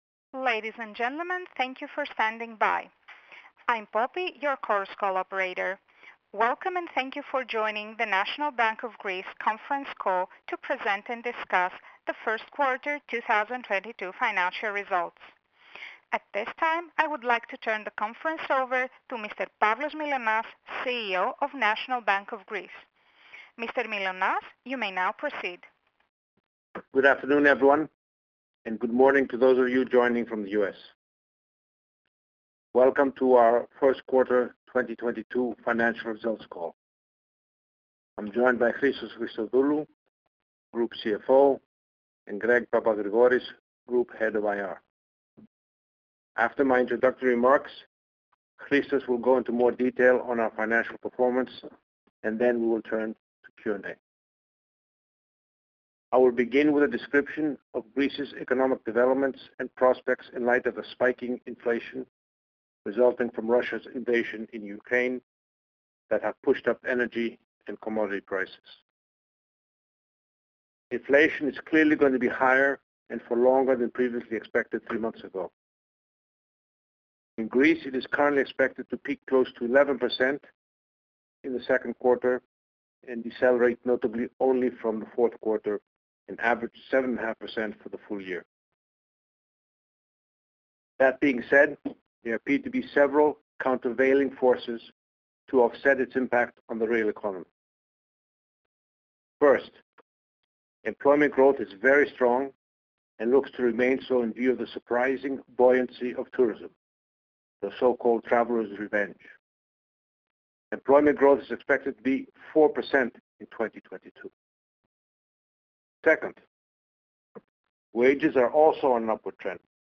Conference Call 4Q25 Results